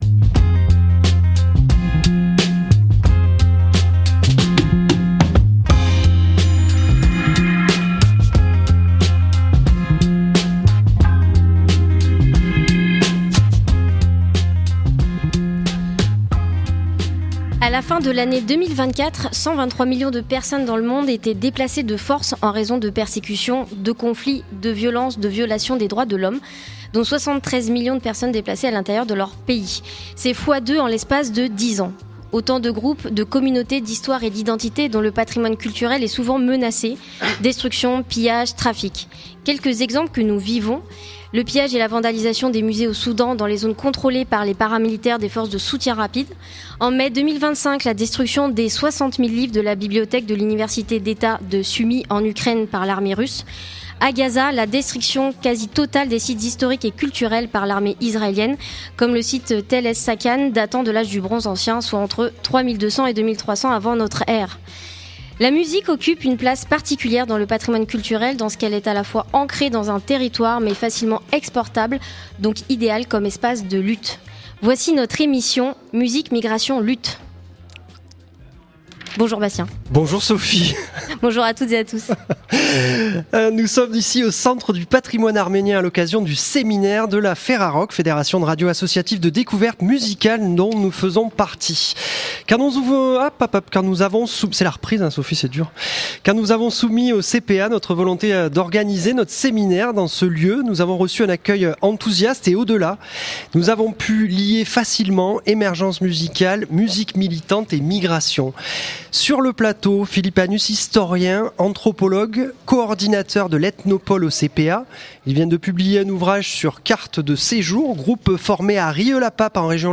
8 mars, 9 voix, et autant de luttes. 1h30 de radio par les animatrices de Canal B à l'occasion de la Journée Internationale de Lutte pour les Droits des Femmes et des Minorités de Genre.